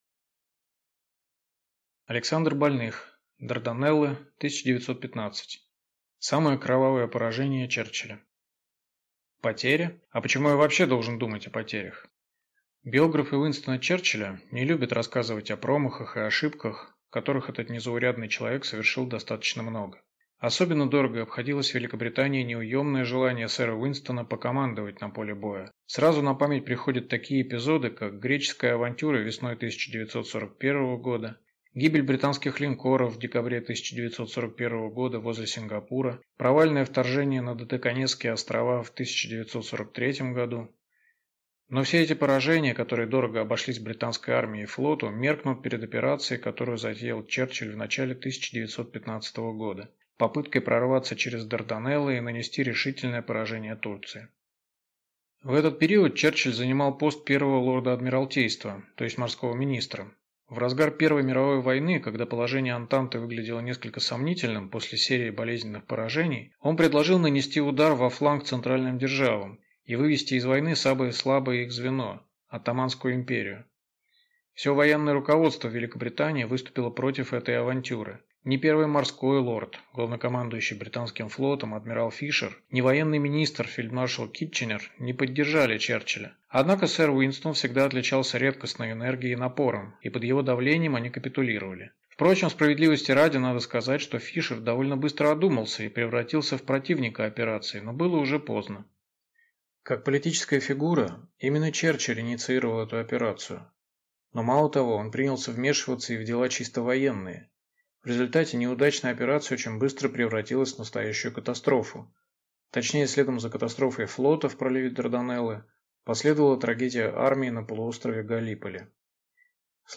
Аудиокнига Дарданеллы 1915. Самое кровавое поражение Черчилля | Библиотека аудиокниг